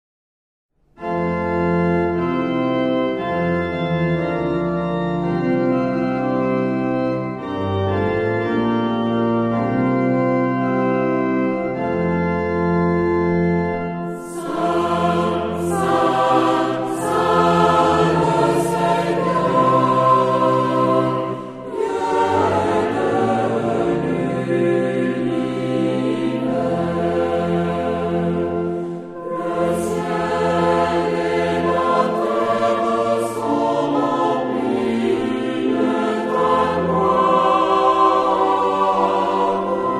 Género/Estilo/Forma: Sagrado ; Misa
Carácter de la pieza : calma
Tipo de formación coral: SATB  (4 voces Coro mixto )
Tonalidad : fa mayor
Uso litúrgico: Sanctus